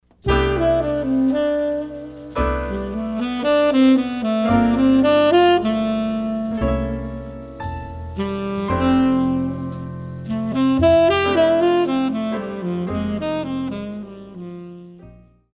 Jazz
Band
Etudes
Blues,Funk
Only backing